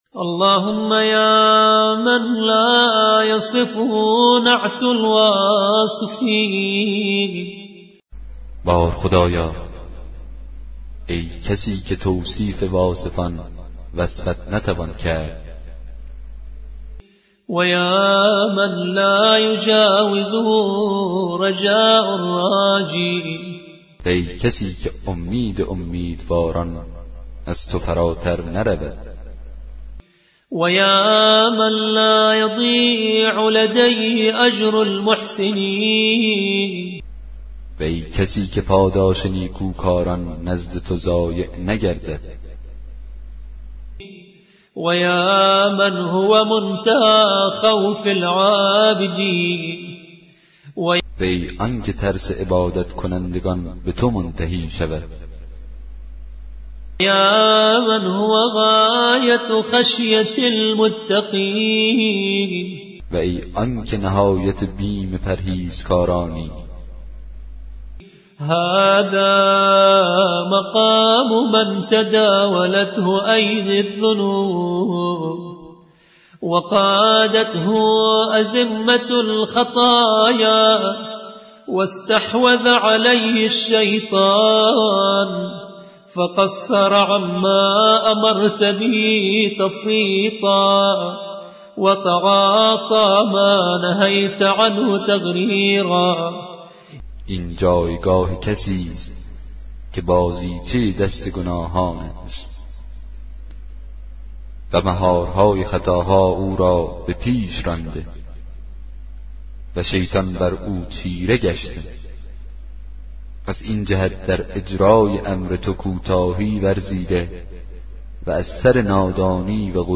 کتاب صوتی دعای 31 صحیفه سجادیه